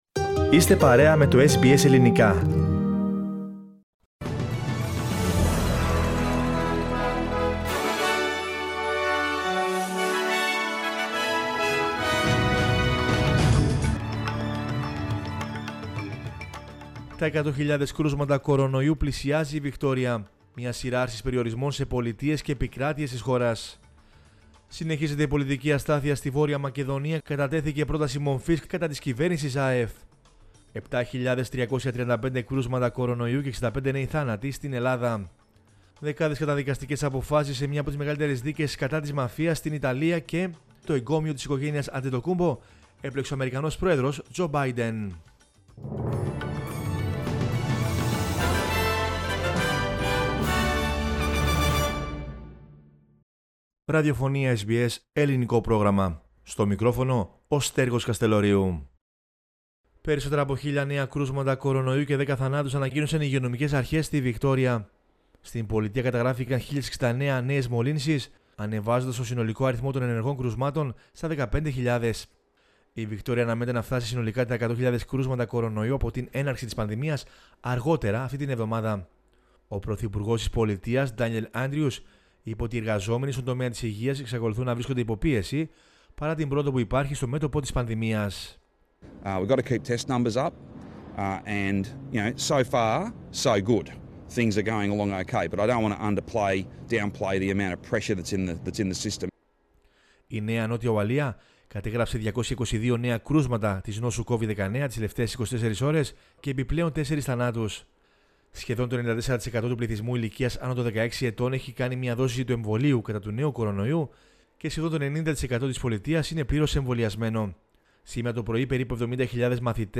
News in Greek from Australia, Greece, Cyprus and the world is the news bulletin of Tuesday 9 November 2021.